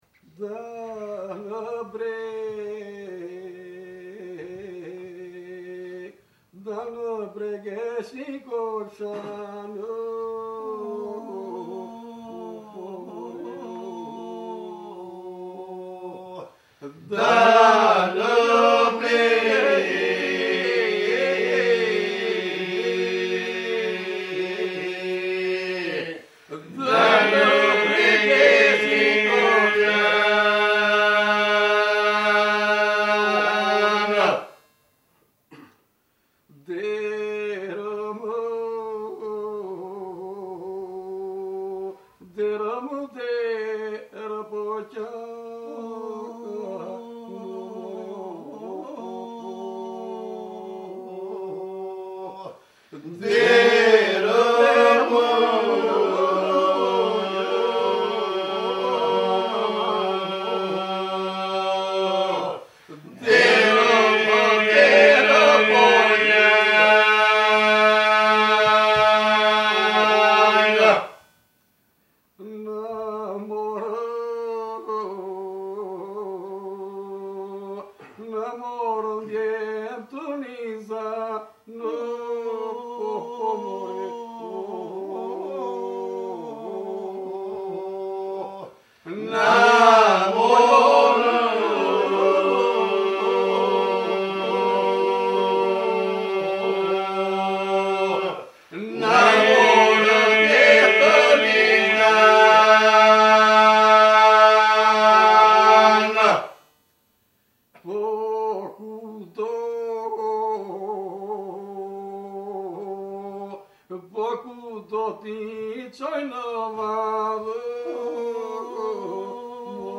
Database of Albanian Folk Iso-Polyphony